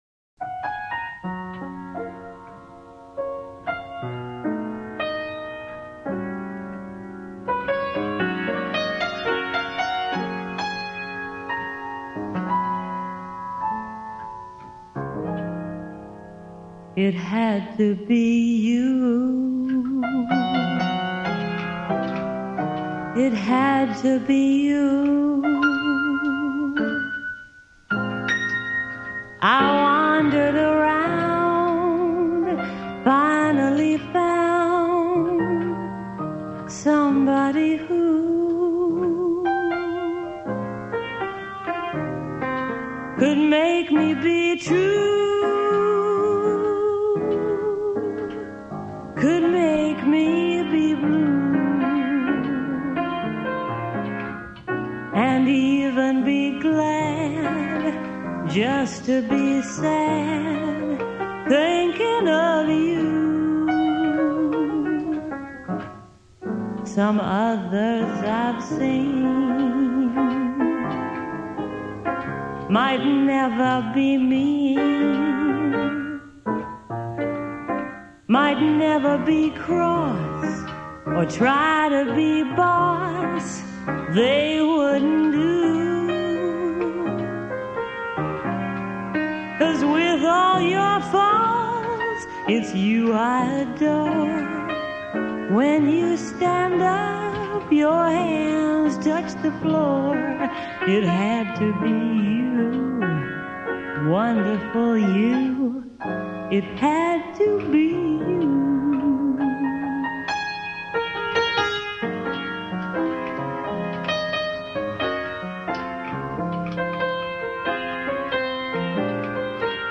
for your phenemonal lovely voice...